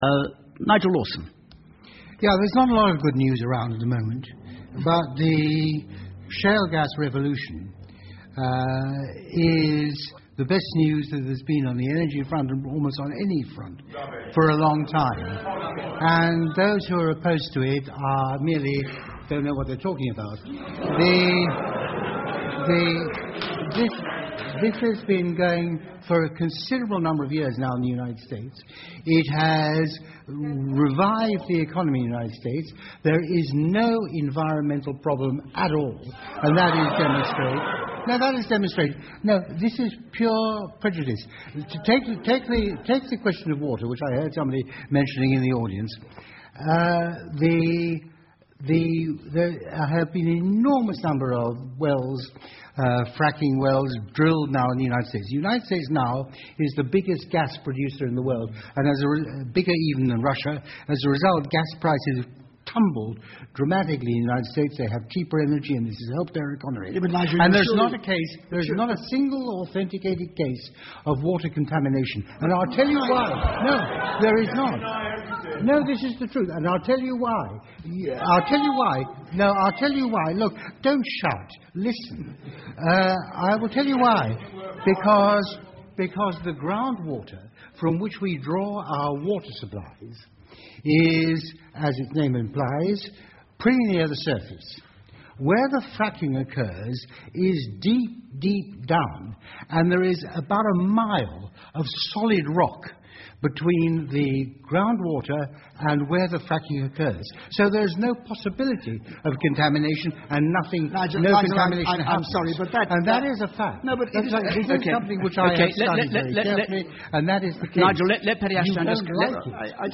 The audience’s reaction to his meanderings says it all really.